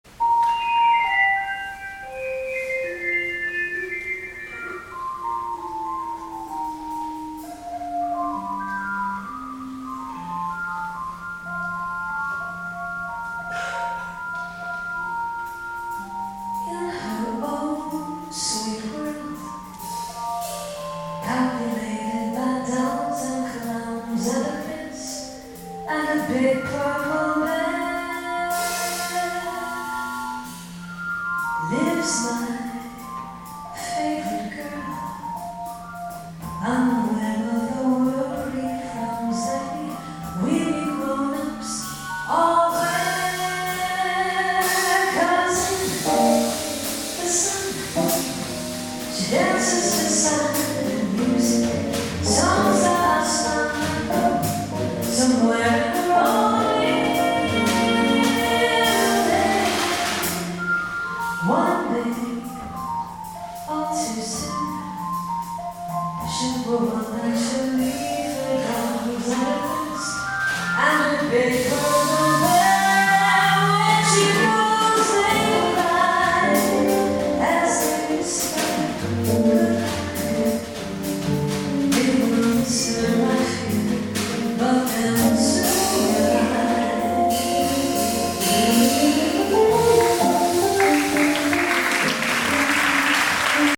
avec une chanteuse
qualité de record pas terrible car minidisc dans le club
c'est la première fois que j'entends une version chantée